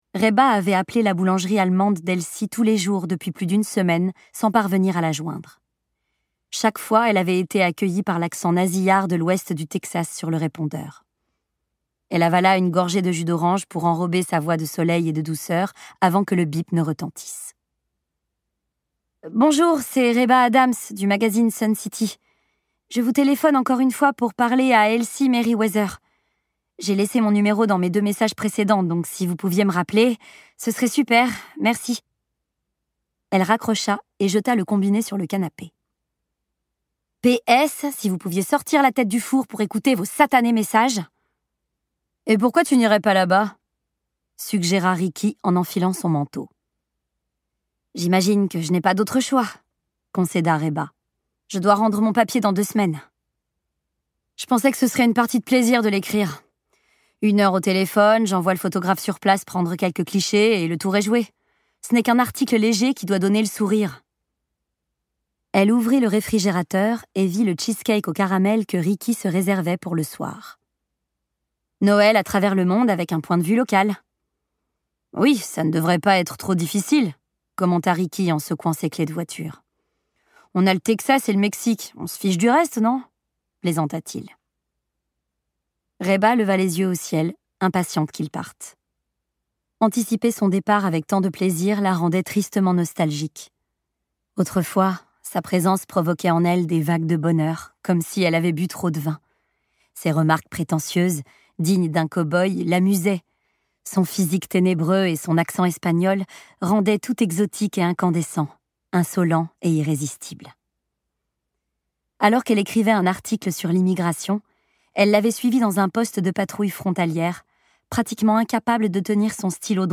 avec son timbre éraillée et son interprétation d'une grande justesse